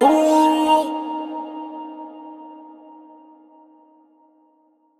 TS Vox_20.wav